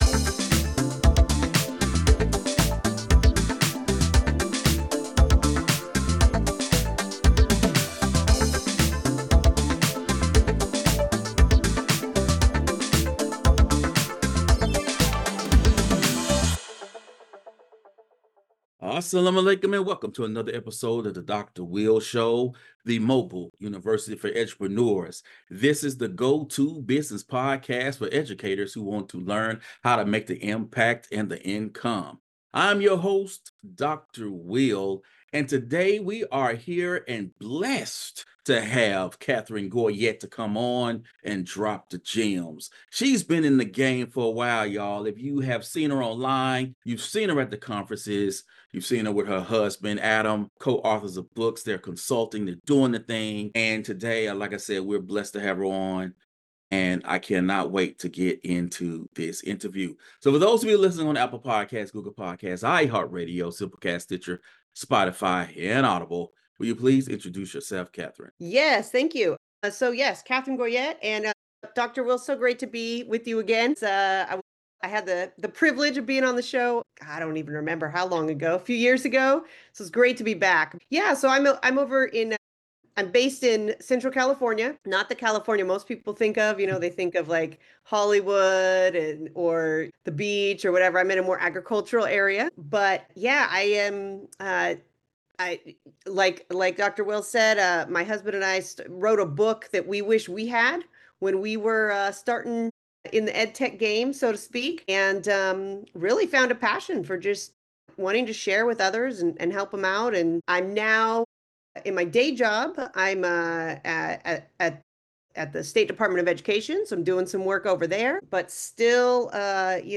Dubbed The Mobile University for Edupreneurs, this is the show where personal development meets purpose-driven living, with a healthy dose of entrepreneurship along the way. Each week, I will bring you insightful, no-fluff conversations with edupreneurs, entrepreneurs, authors, and thought leaders who share their journeys, strategies, and real-world advice on personal growth, personal finance, and creating a life you get to live, not one you have to live.